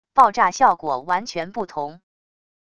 爆炸效果完全不同wav音频